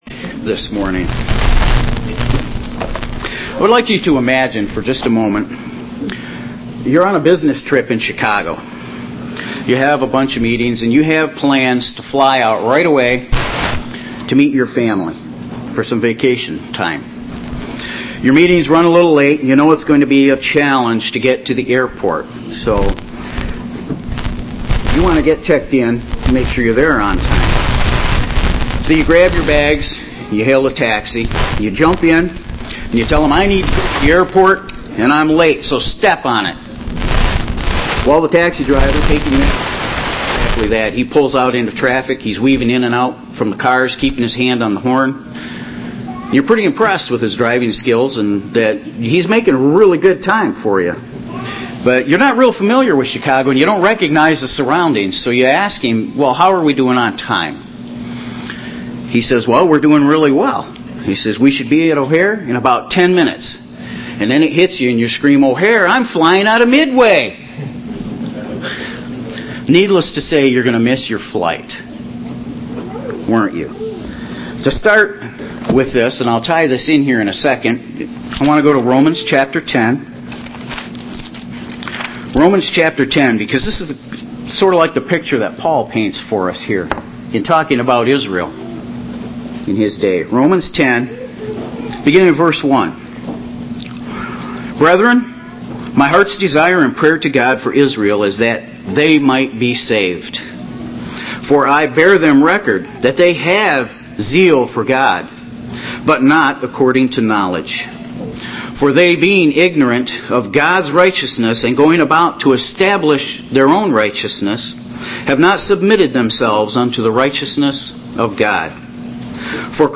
This message was given on the Last Day of Unleavened Bread.
Given in Grand Rapids, MI
UCG Sermon Studying the bible?